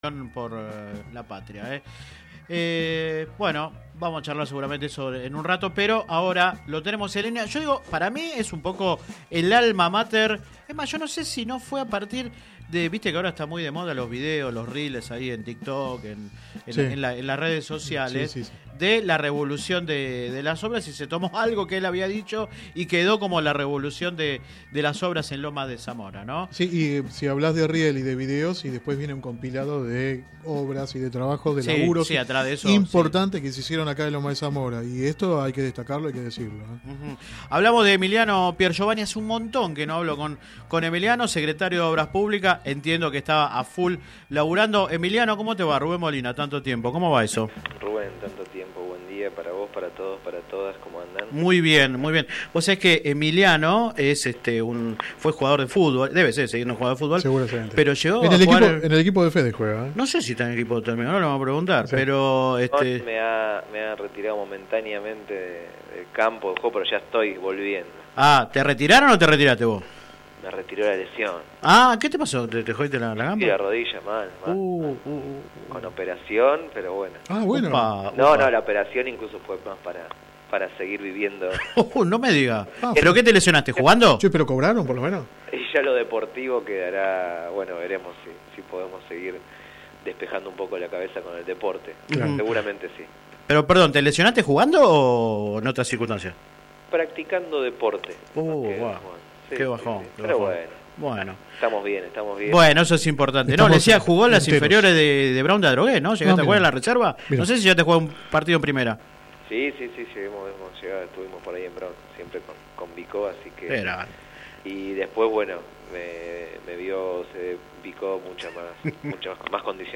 El secretario de Obras Públicas y Servicios Públicos de la municipalidad de Lomas de Zamora, Emiliano Piergiovanni, habló en exclusivo en el programa radial Sin Retorno (lunes a viernes de 10 a 13 por GPS El Camino FM 90 .7 y AM 1260). Ponderó la denominada “revolución de las obras” en la comuna y le bajó el tono a las críticas de la oposición por falta de planificación. Al mismo tiempo expresó su apoyo a la candidatura a intendente de Federico Otermín.